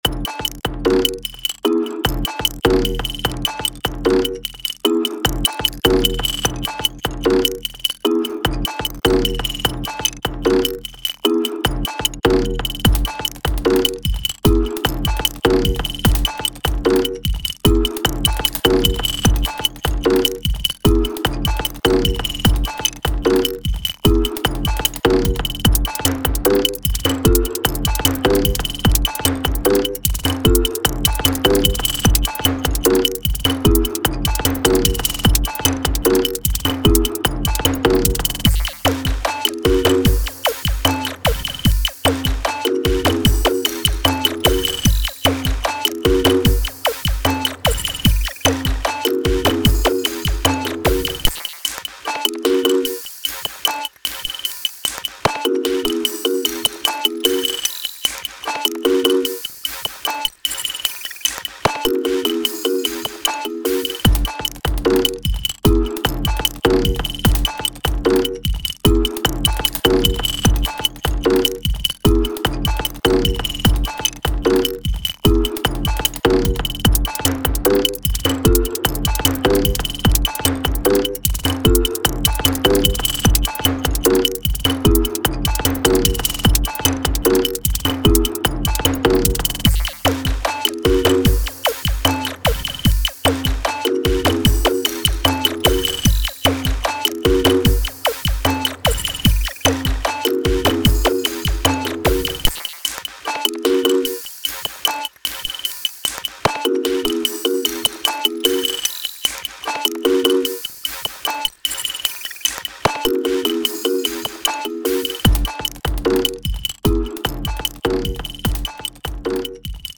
短い音を散りばめたリズムトラックがメインの楽曲になっており、白っぽくてニュートラルな雰囲気を狙っている。